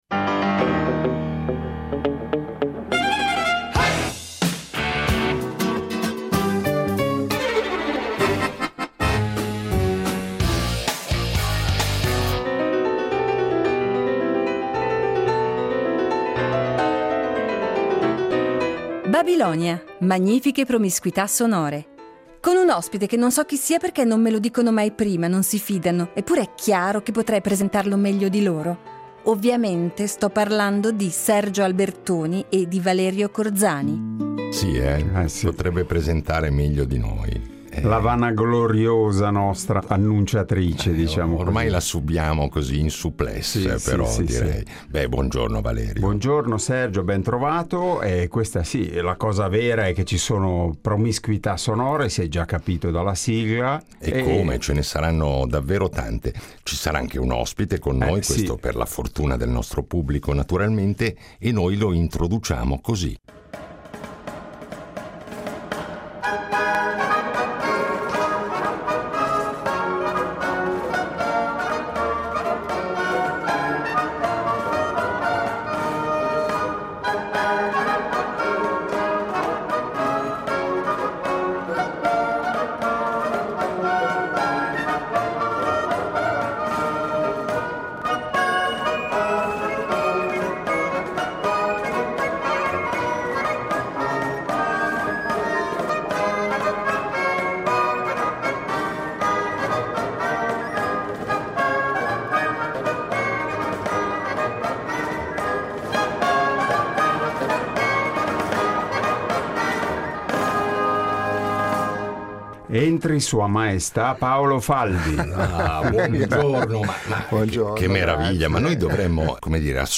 Una carriera decisamente importante, insomma, che come spesso ci accade proveremo ad attraversare senza riuscirci compiutamente, ma incontrando un buon numero di esempi sonori illuminanti.